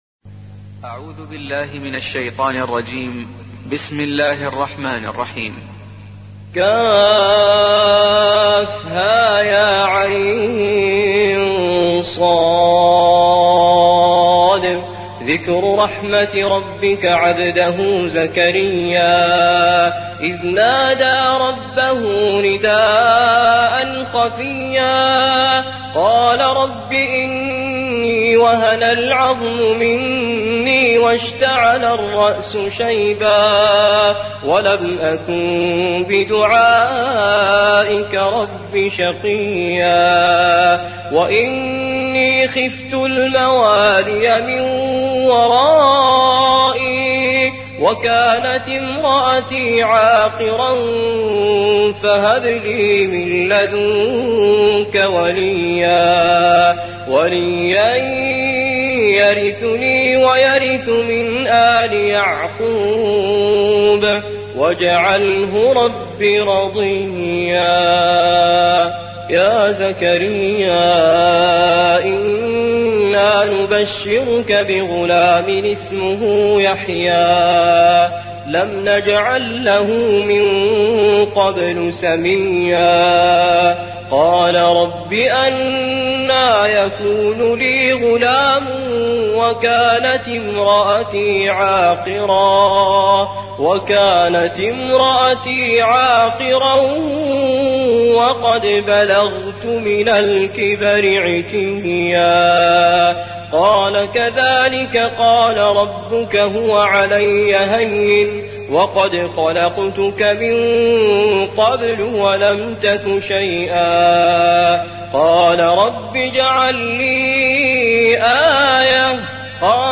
تحميل : 19. سورة مريم / القارئ توفيق الصايغ / القرآن الكريم / موقع يا حسين